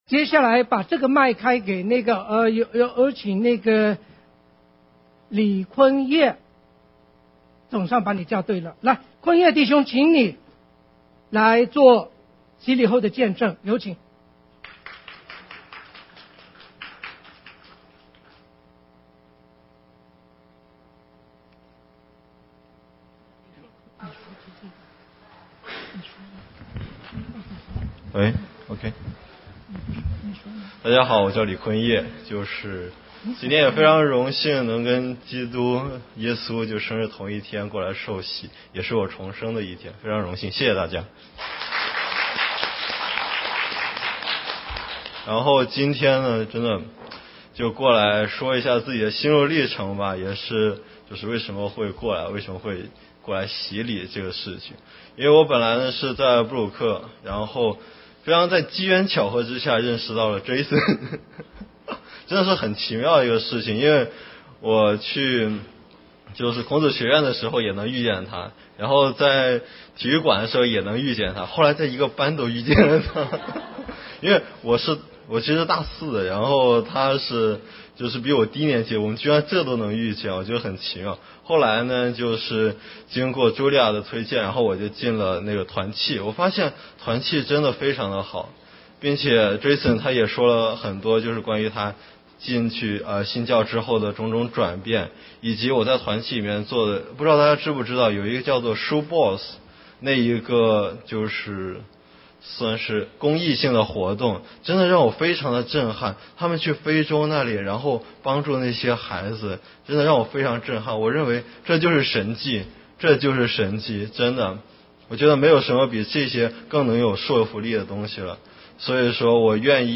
17年圣诞节受洗见证分享
12月24日，在圣诞节主日，有7位弟兄姐妹接受耶稣为他们的救主，受洗归入主的名下，成为神的儿女！他们各自作了信主经历的分享。